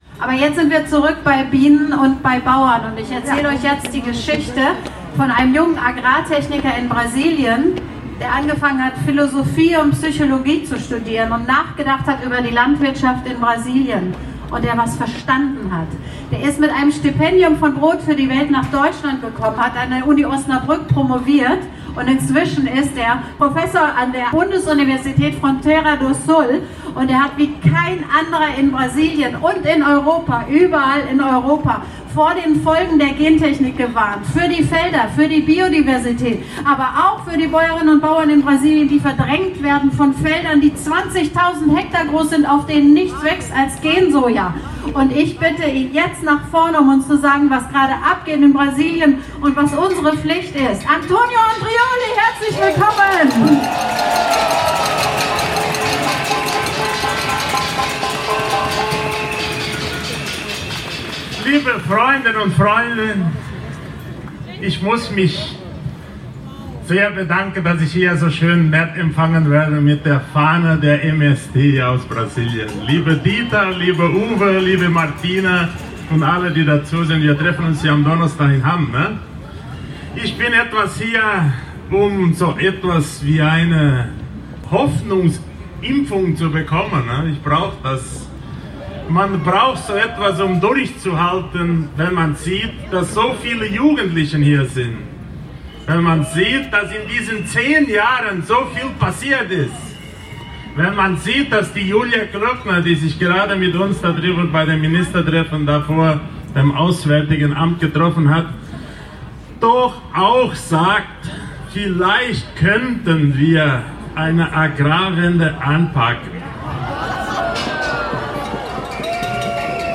Demonstration: 10. „Wir haben es satt!“-Demonstration (Audio 10/20)